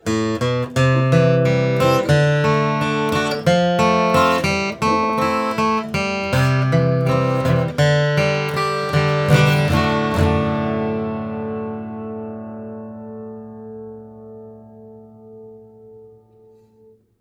Three feet? Sounds like 10cms! Good bold projection.